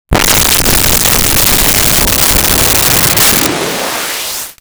Alien Woosh 01
Alien Woosh 01.wav